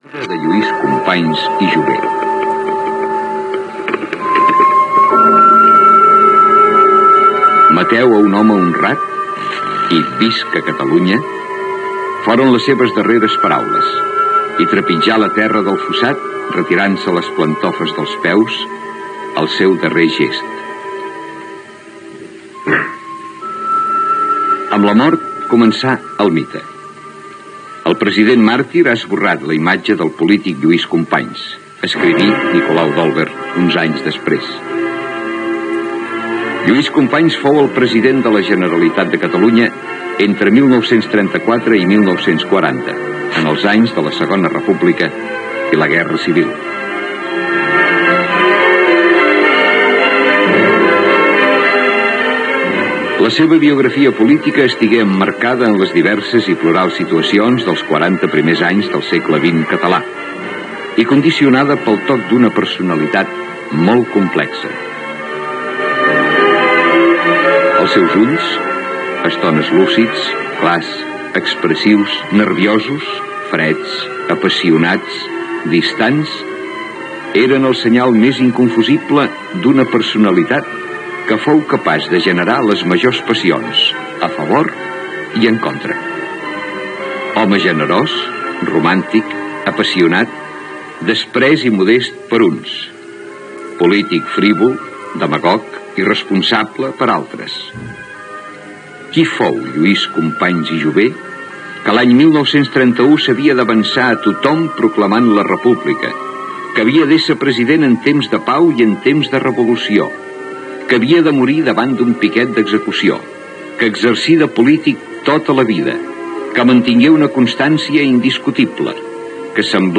Amb declaracions del president de la Generalitat Josep Tarradellas Gènere radiofònic Informatiu